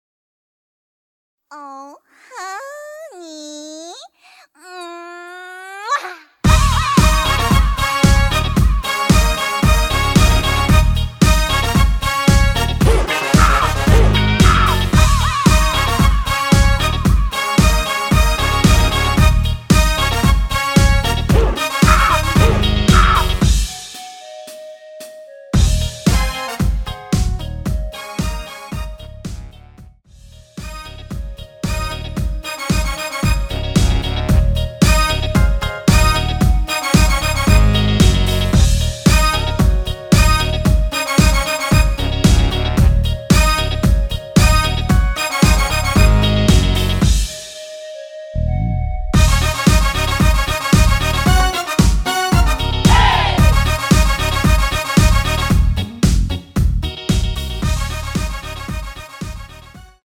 원키 멜로디 포함된 MR입니다.(미리듣기 확인)
Ebm
앞부분30초, 뒷부분30초씩 편집해서 올려 드리고 있습니다.
중간에 음이 끈어지고 다시 나오는 이유는